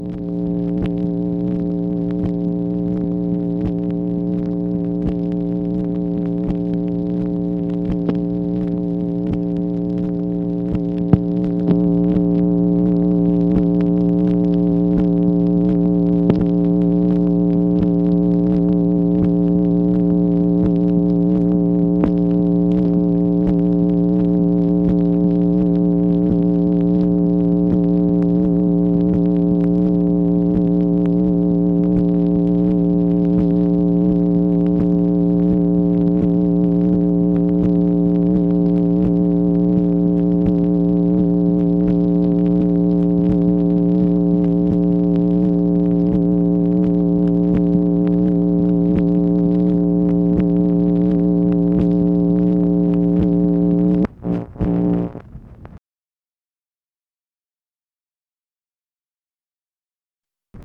MACHINE NOISE, October 1, 1965
Secret White House Tapes